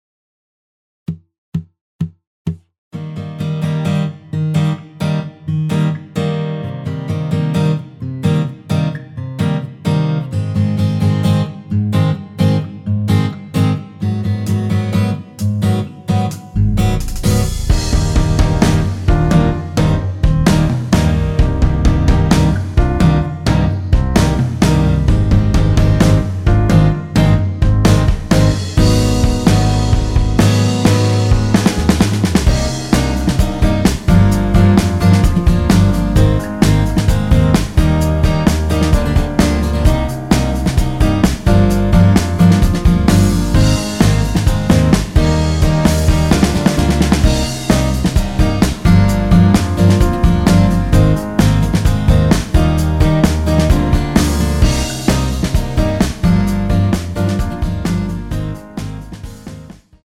전주 없이 시작하는 곡이라 4박 카운트 넣어 놓았습니다.(미리듣기 확인)
원키에서(-2)내린 MR입니다.
Eb
앞부분30초, 뒷부분30초씩 편집해서 올려 드리고 있습니다.